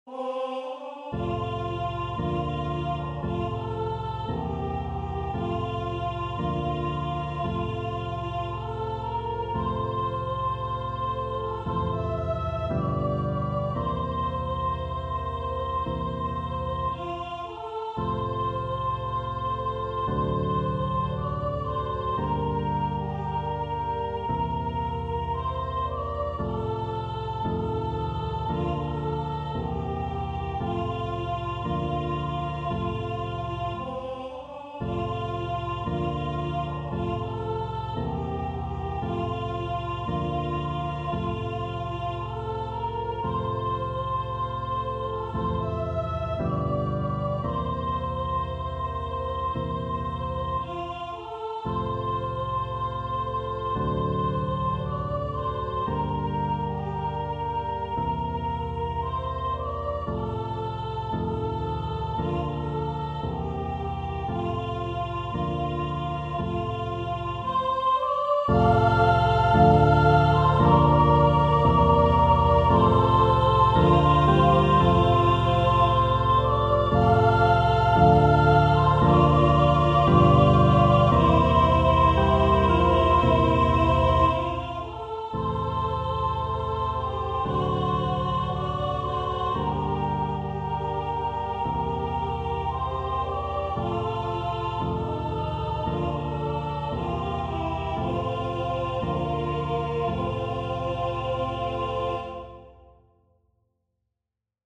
Genere: Religiose